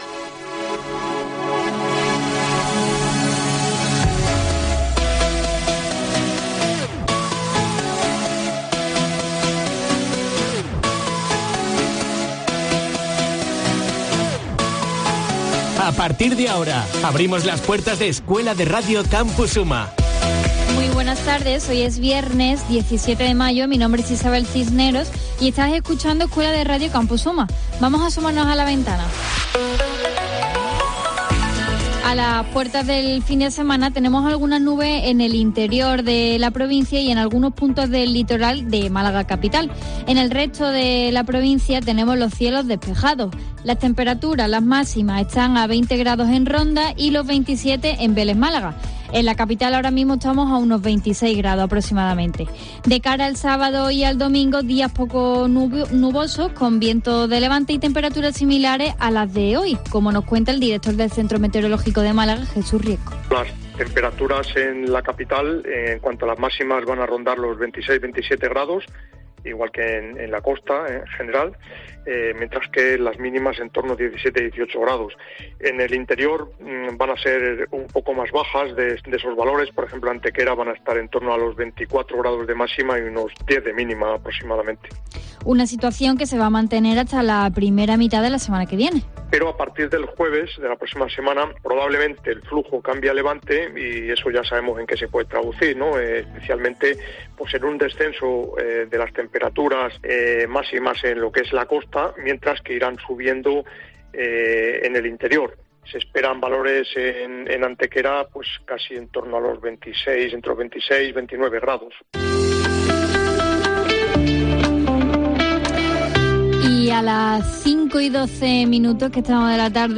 AUDIO: Un programa hecho por estudiantes de la Universidad de Málaga